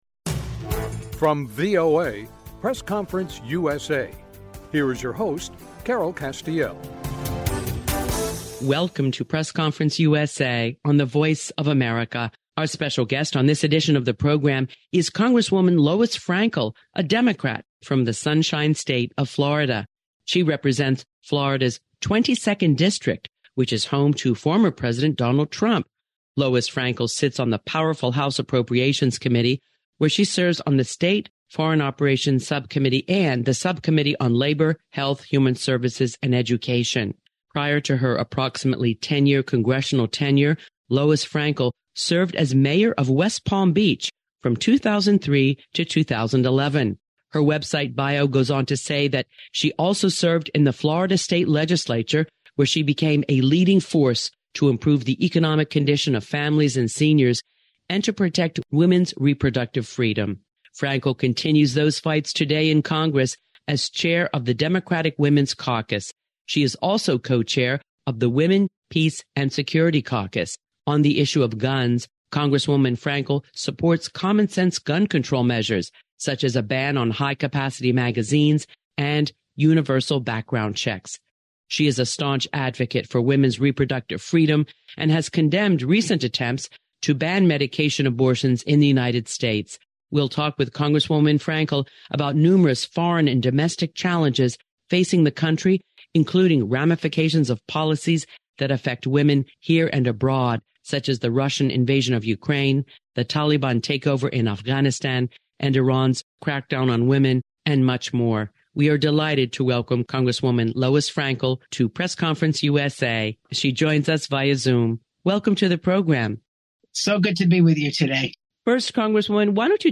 A Conversation with Congresswoman Lois Frankel, D-FL